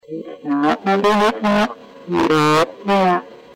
In questa brevissima registrazione ricevuta il 24 novembre 2008, si presenta un'entità sconosciuta che, però, dal tono che usa, sembra avere una certa autorità.